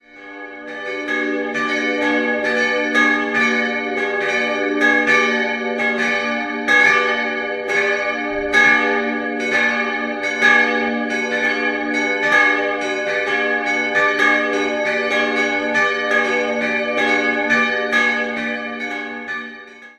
Im Gesamtbild handelt es sich aber um einen spätbarocken Sakralbau, der 1741 bis 1748 von Jakob Aman aus Kelheim geplant und errichtet wurde. 3-stimmiges Geläut: gis'-d''-fis'' Die beiden kleinen Glocken sind unbezeichnet und dürften aus dem 14. Jahrhundert stammen, die große wurde 1872 von Spannagl gegossen.